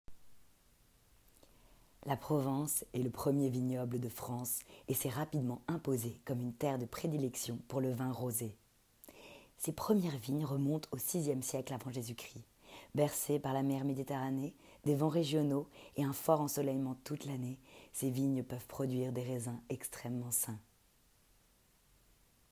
Bande-démo
Voix off casting